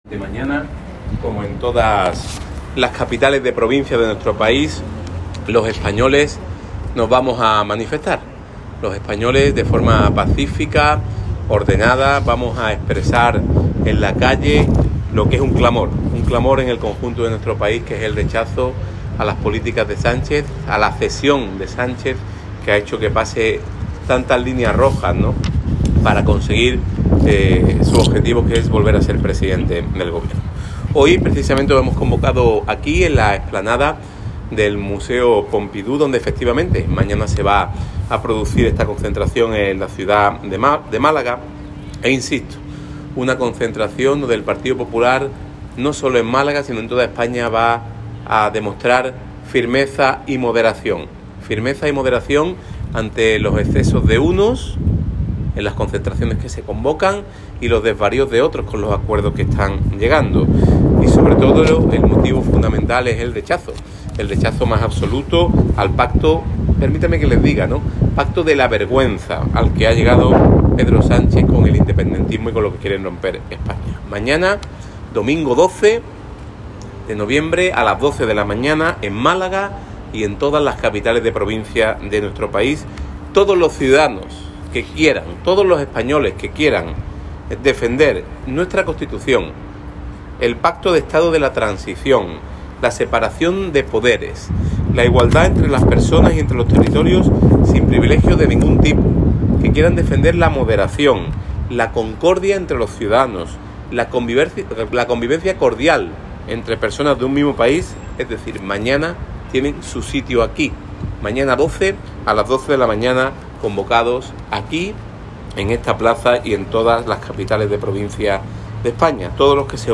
En este sentido se ha pronunciado Bendodo este sábado en Málaga en el entorno del Centre Pompidou, lugar donde el PP ha convocado este domingo, 12 de noviembre, a una movilización contra la amnistía que también tiene lugar en el resto de capitales de provincia del país.